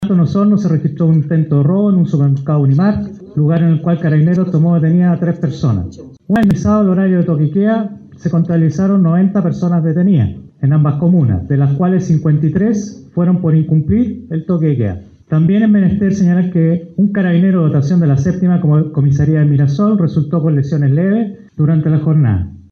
Pasadas las 11 de la mañana de este jueves, en dependencias del Casino de Suboficiales de la FACh en Puerto Montt, el Jefe de la Defensa Nacional para el Estado de Excepción Constitucional de Emergencia en Osorno y Puerto Montt, General de Brigada Aérea, Cristian Eguía, entregó un reporte actualizado de la jornada de manifestaciones. El General Eguía indicó que como resultado durante el toque de queda, se registraron 90 detenidos, donde 53 corresponden al incumplimiento de dicha medida.